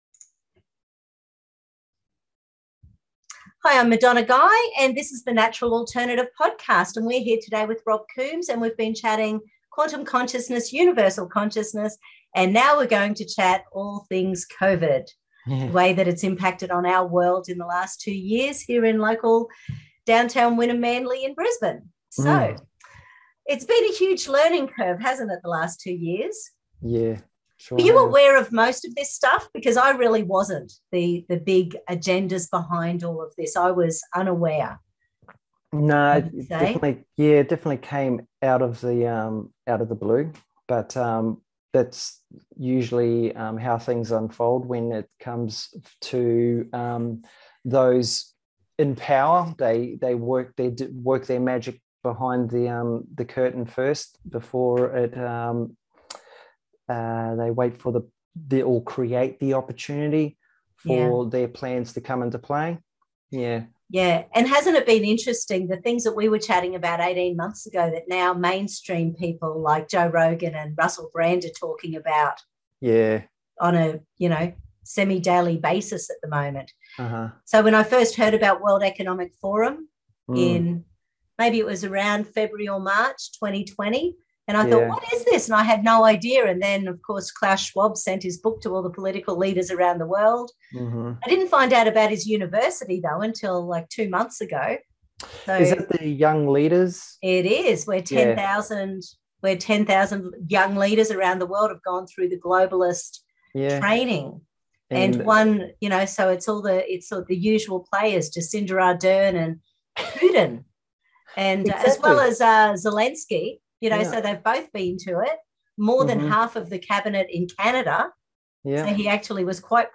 Here's an open discussion about current events and how it's affected our world...